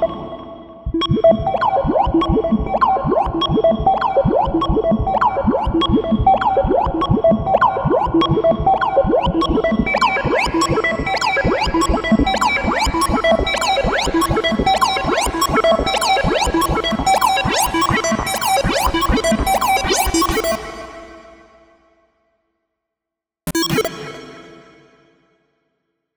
Underwater-Echo-Sequence-A-200.wav